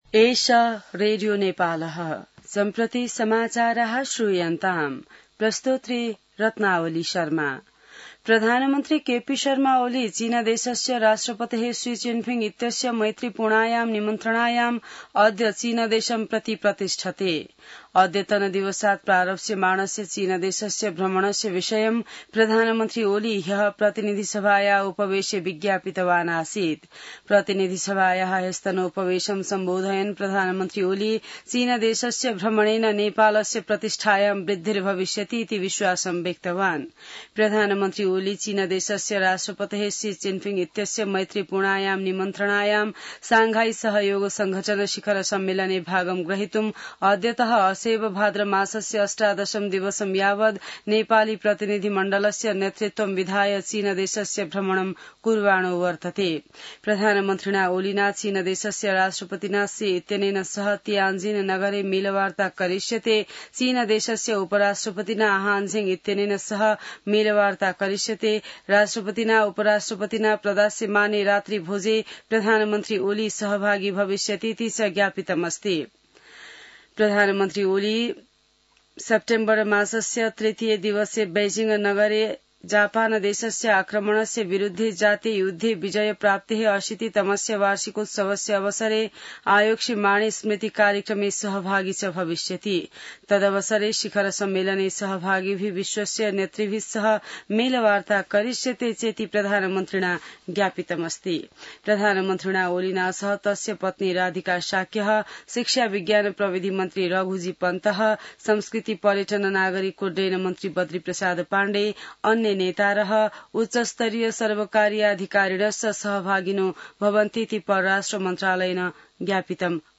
संस्कृत समाचार : १४ भदौ , २०८२